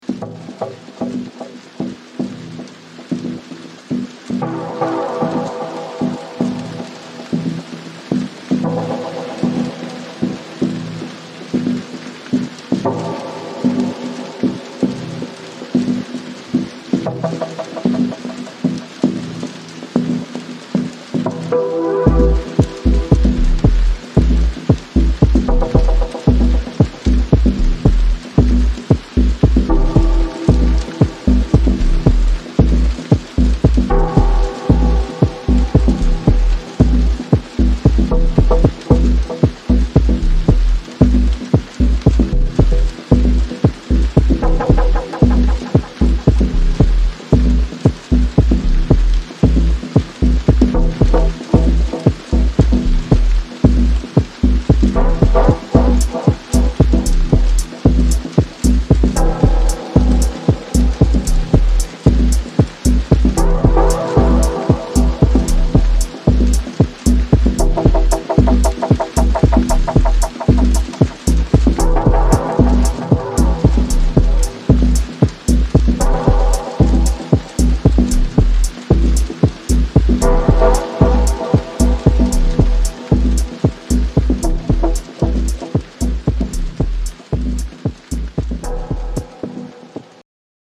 雨が降り注ぐ114BPMインスト